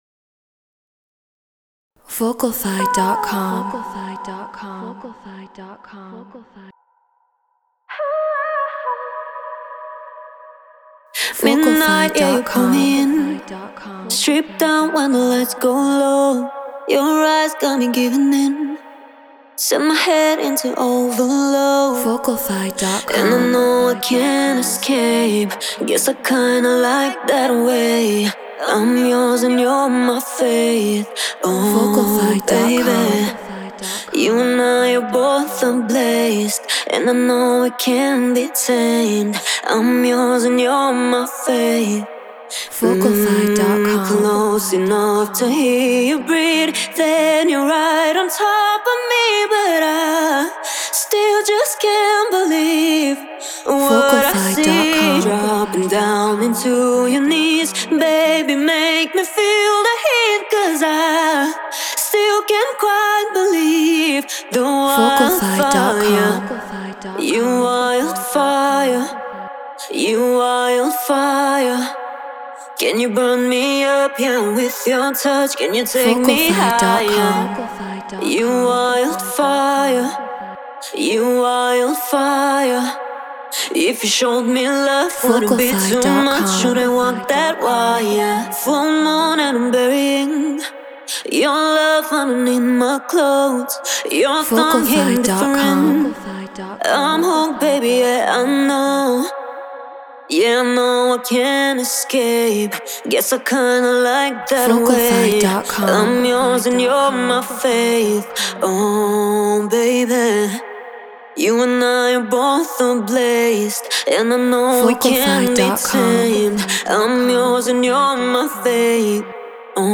Drum & Bass 174 BPM G#m